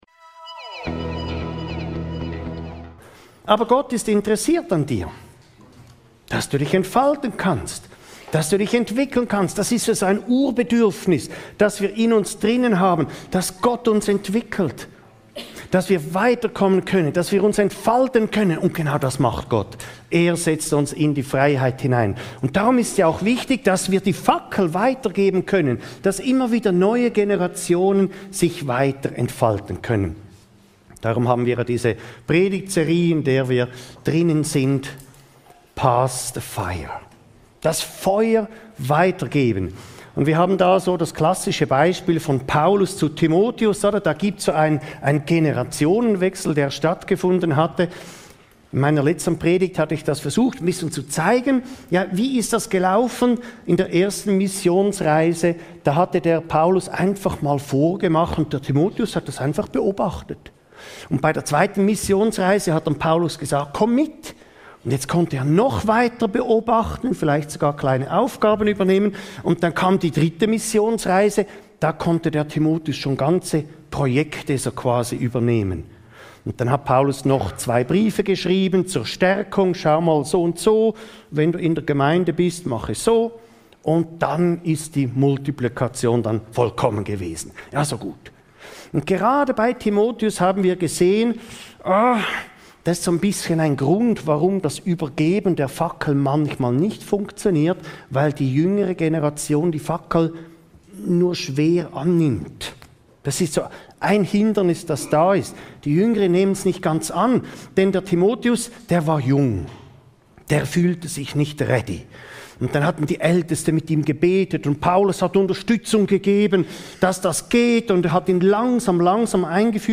Junge nachnehmen ~ Your Weekly Bible Study (Predigten) Podcast